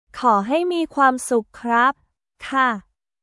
コー ハイ ミー クワームスック クラップ/カー